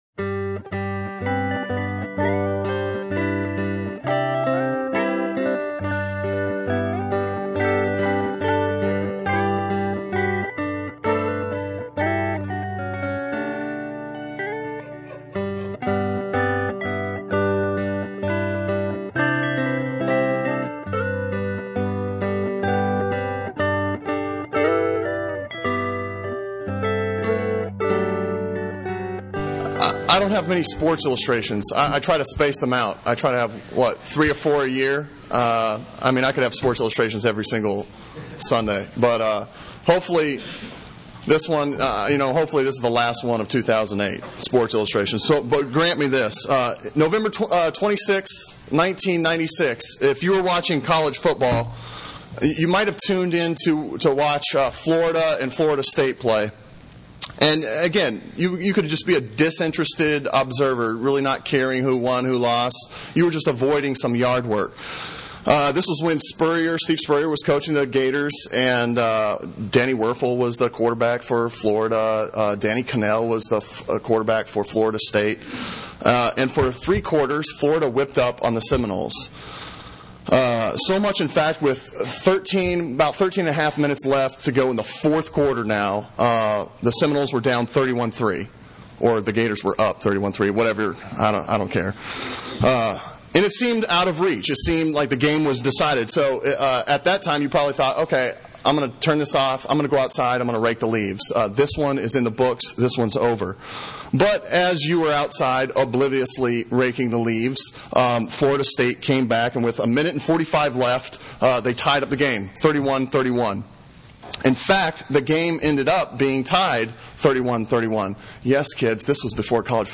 « Back to sermons page Dancing on Gravestones Sermon from November 2